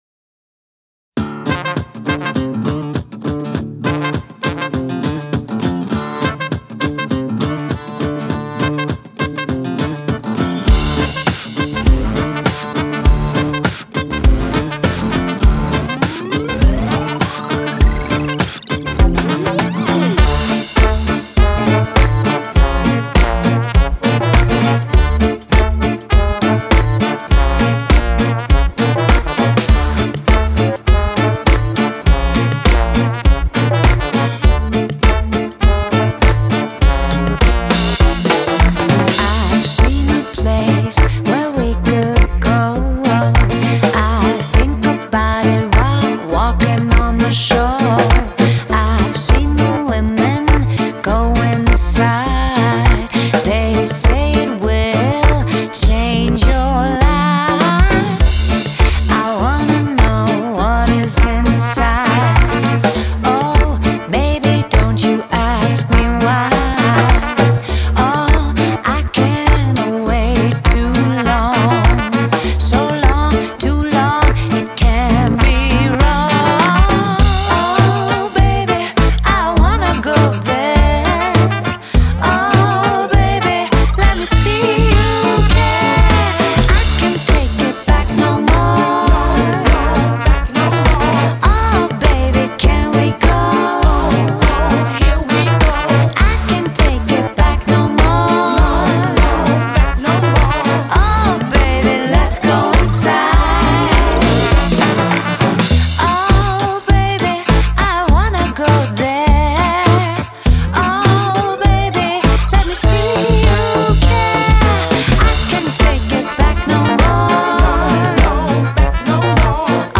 Genre: Electronic, Gypsy Jazz, Balkan, Folk, World